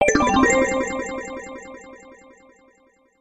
鉄琴の一種のヴィブラフォン音色。